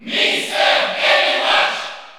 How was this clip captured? Mr._Game_&_Watch_Cheer_French_PAL_SSBU.ogg